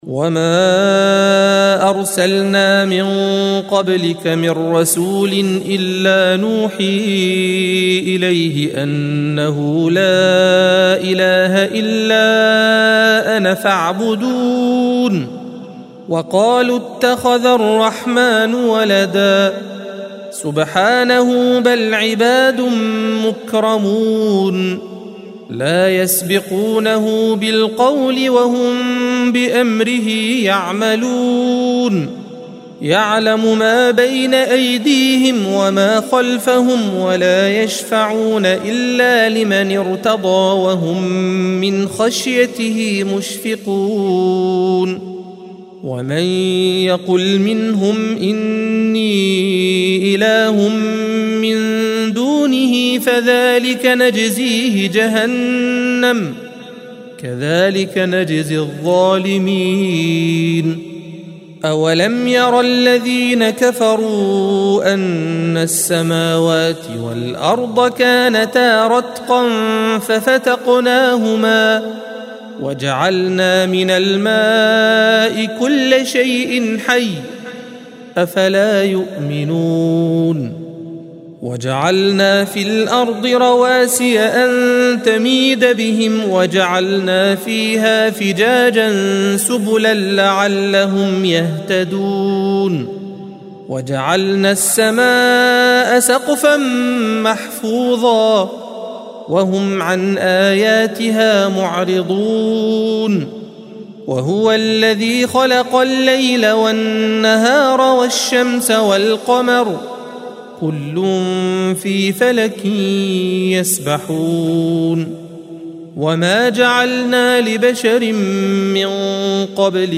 الصفحة 324 - القارئ